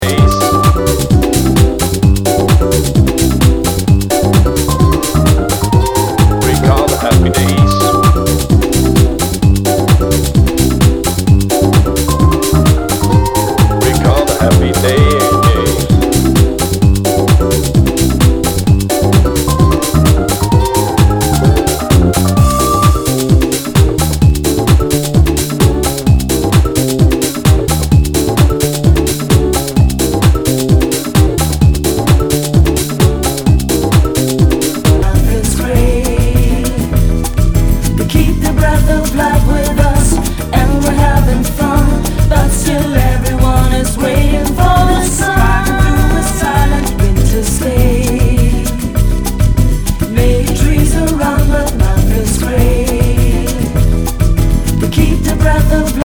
HOUSE/TECHNO/ELECTRO
ナイス！ボッサ・ヴォーカル・ハウス！
全体にチリノイズが入ります